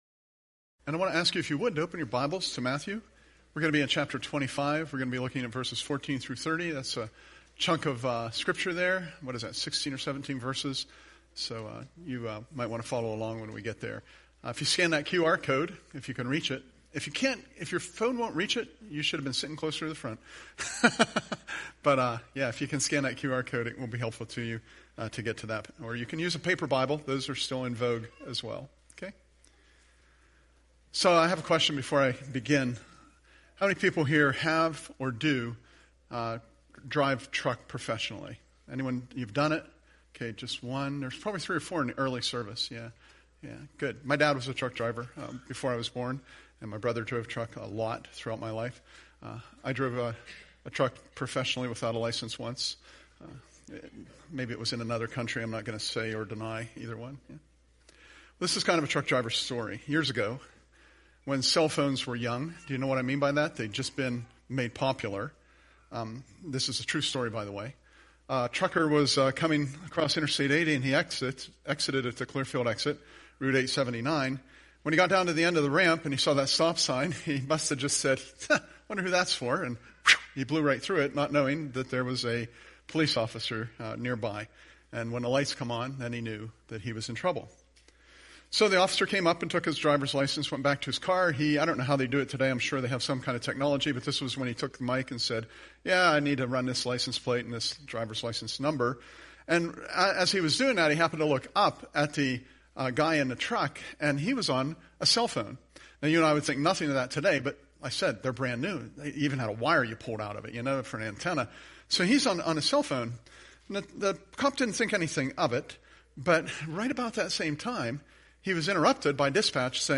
Presented at Curwensville Alliance on 03/02/25